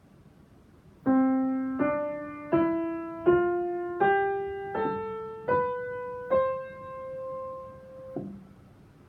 そうしてたどり着く、シャープなしのハ長調。馴染みのソファにどさっと座ったような安心の響きです。
scale_C.m4a